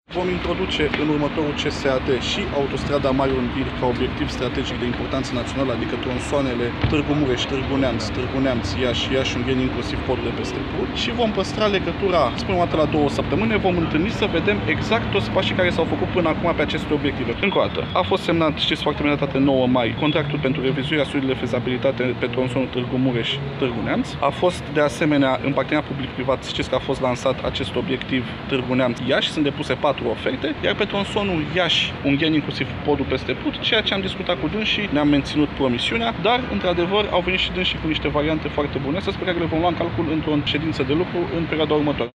Declaraţia a fost făcută la finalul unei întrevederi pe care reprezentanţii asociaţiilor ce militează pentru autostrada A 8 au avut-o, la Iaşi, cu premierul Viorica Dăncilă şi ministrul Transporturilor, Răzvan Cuc.